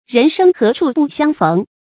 人生何处不相逢 rén shēng hé chù bù xiāng f 成语解释 〖释义〗指人与人分手后总是有机会再见面的。